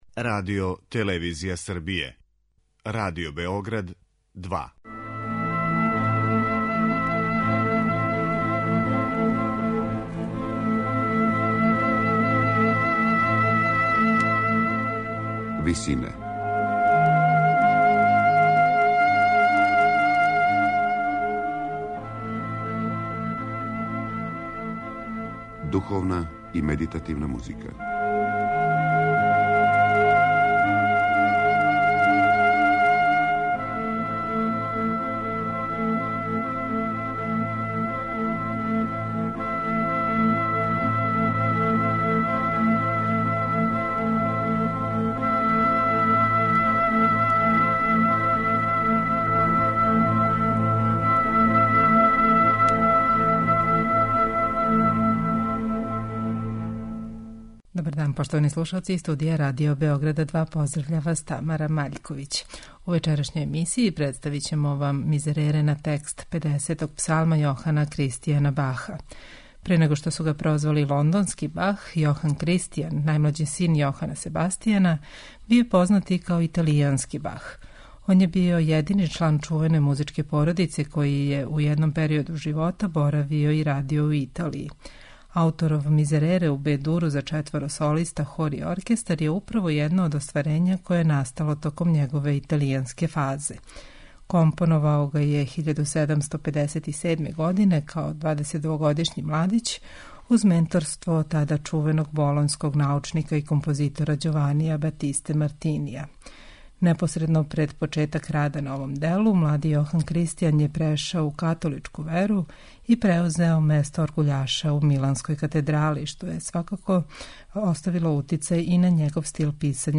Слушаћете духовну музику Јохана Кристијана Баха, најмлађег сина великог Јохана Себастијана.
Представићемо његов Мизерере у Бе-дуру за четворо солиста, хор и оркестар, као и одломке из Реквијема.